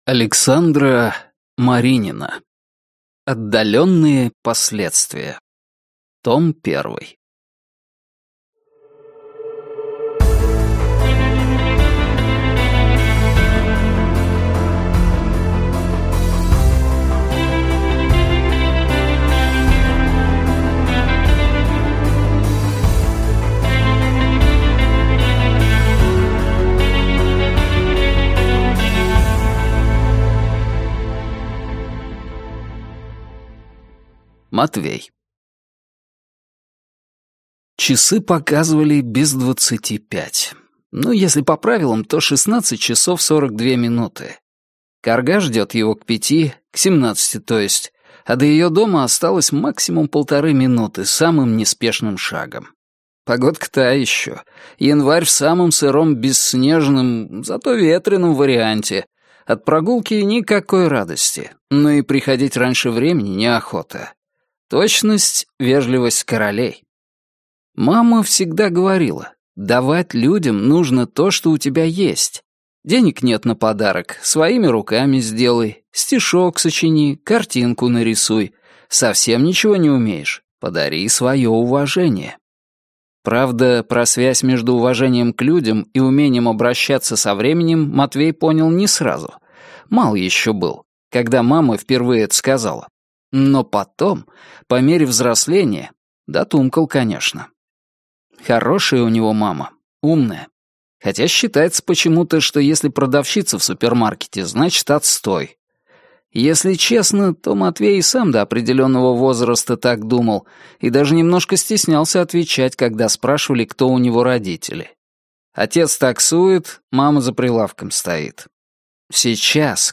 Аудиокнига Отдаленные последствия. Том 1 | Библиотека аудиокниг